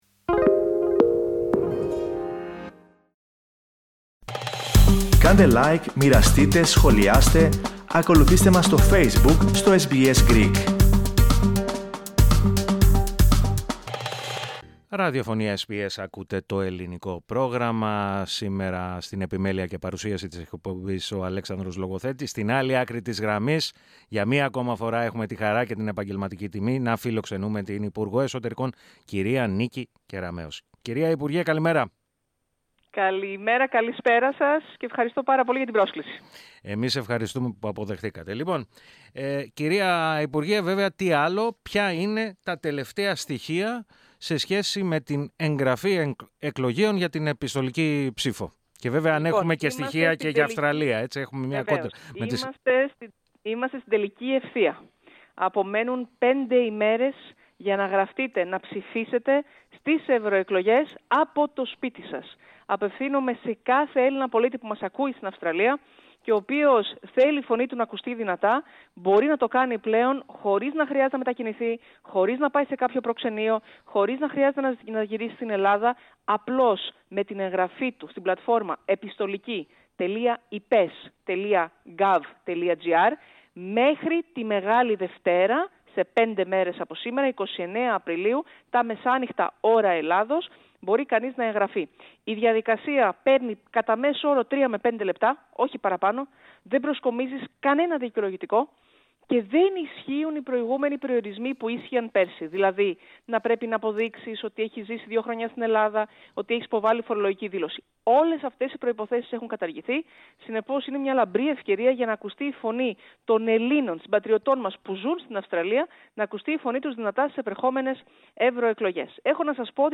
Η υπουργός Εσωτερικών Νίκη Κεραμέως, μίλησε στο Ελληνικό Πρόγραμμα της ραδιοφωνίας, με αφορμή το γεγονός ότι στις 29 Απριλίου, λήγει η προθεσμία εγγραφής εκλογέων στους καταλόγους για την επιστολική ψήφο.